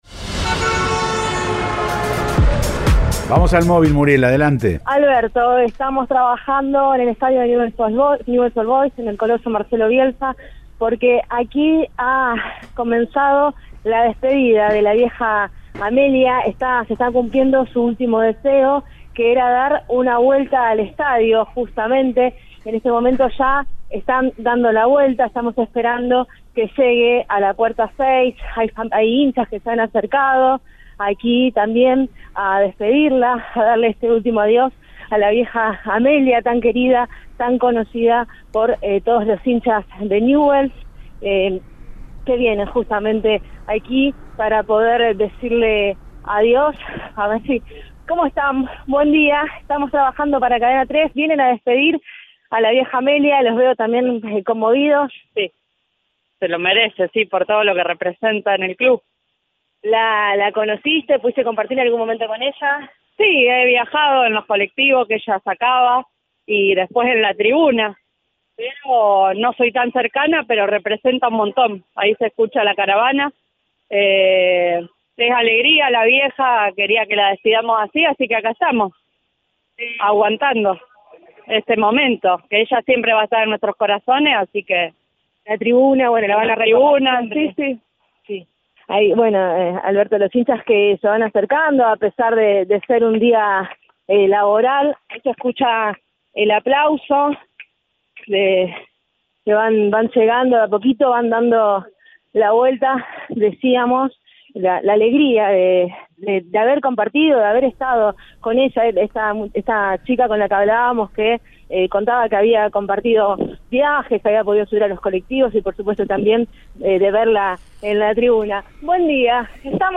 Su último deseo era que su cortejo pase por el estadio de Newell’s Old Boys y este jueves familiares y simpatizantes la homenajearon con emoción y respeto.